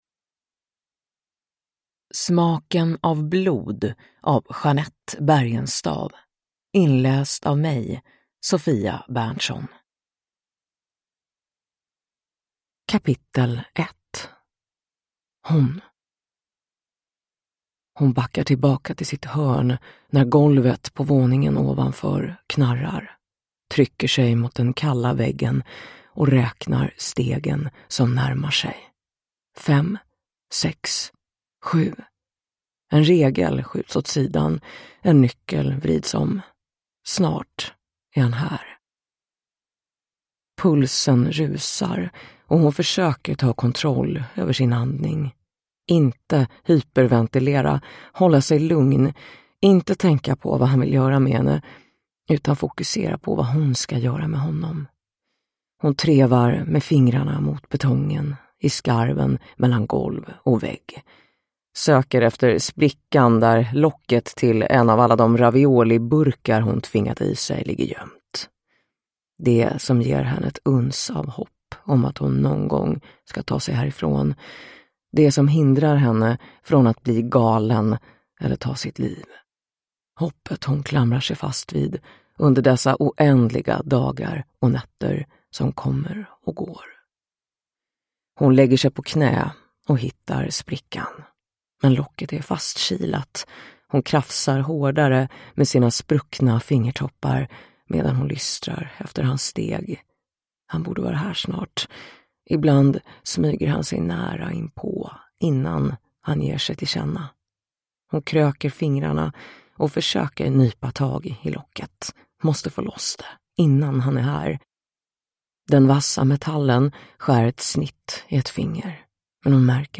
Smaken av blod – Ljudbok